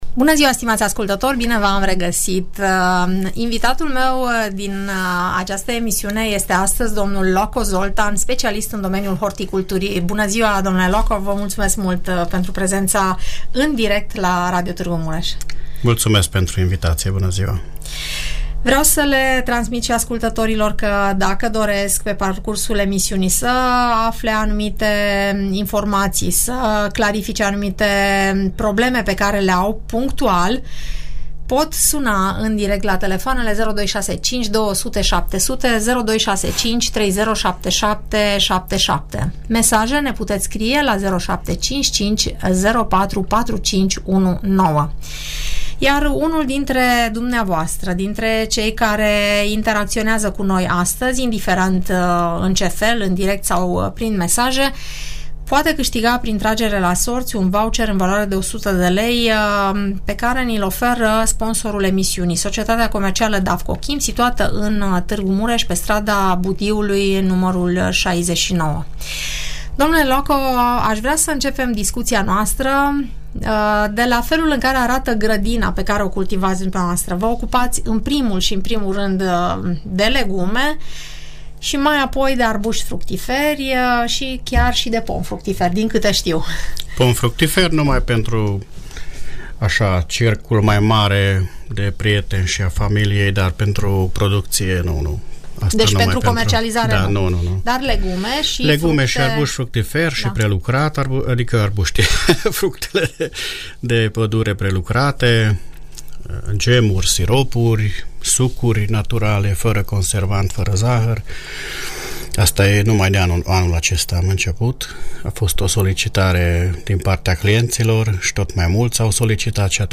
Învățăm meserie de la cei care cultivă și își perfecționează tehnicile agricole prin diferite metode, dar mai ales naturale. Ascultă emisiunea „Părerea ta”, realizată la Radio Tg Mureș